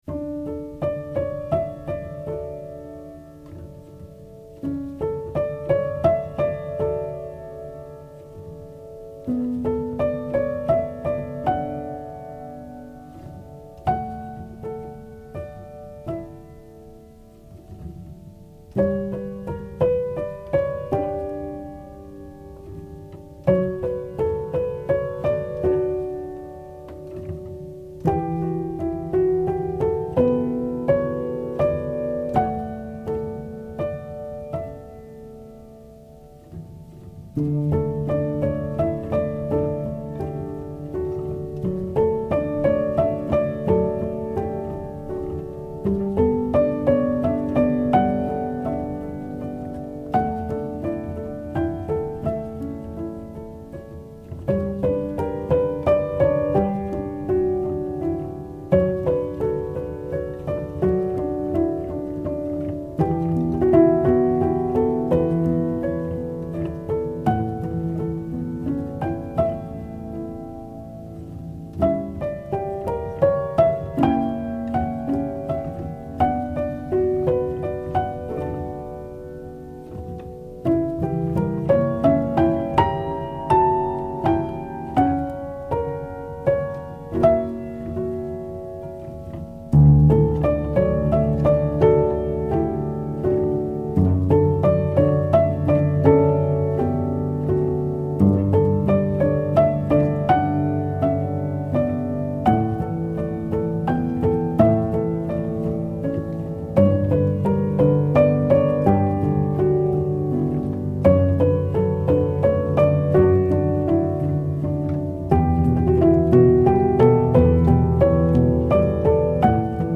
آرامش بخش پیانو مدرن کلاسیک موسیقی بی کلام
پیانو آرامبخش